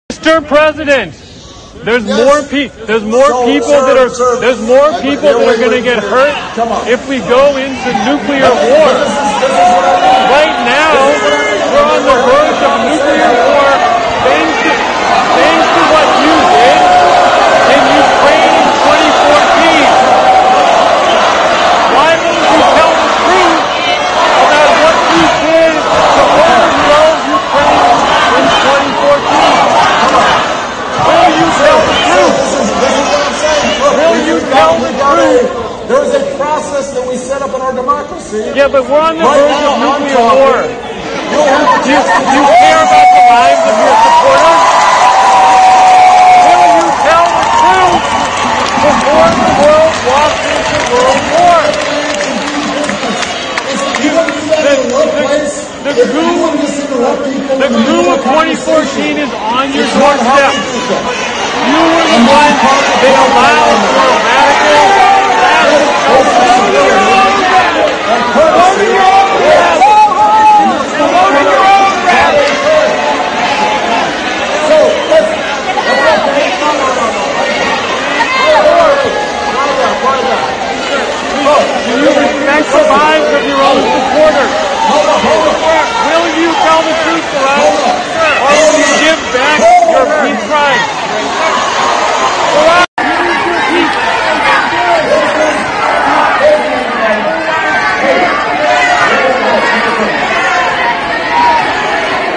Hier wird Obama bei einer Veranstaltung auf den von den USA provozierten 3 Weltkrieg angesprochen, der nuklear werden kann. Beängstigend wie die Masse reagiert und der einzig Aufgewachte abgeführt wird.